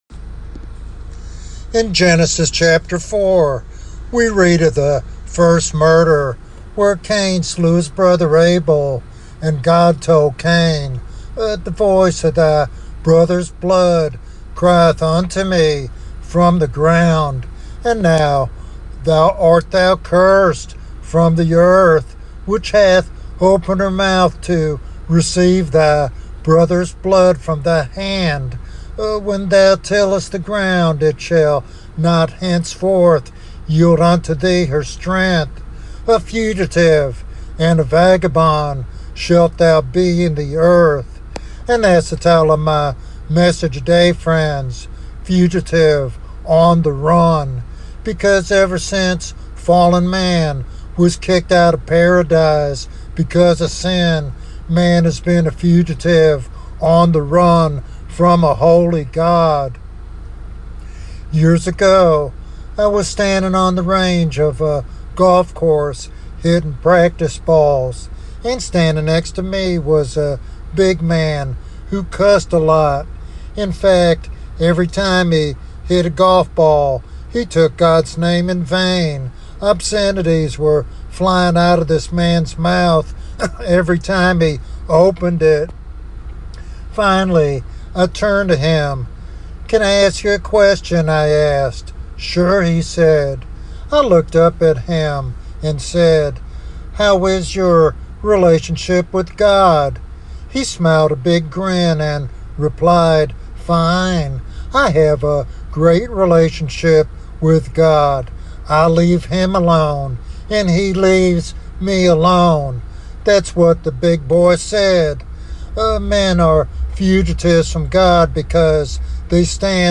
The sermon challenges listeners to confront their relationship with God and choose surrender over judgment.